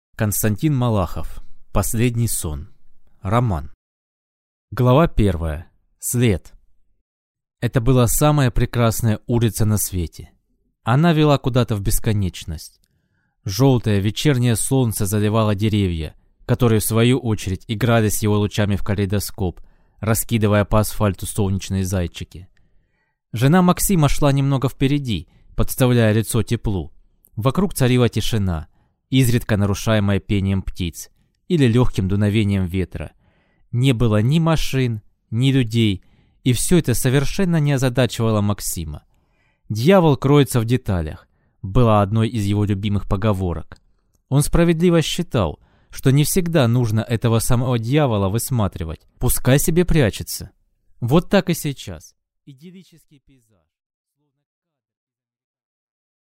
Аудиокнига Последний сон | Библиотека аудиокниг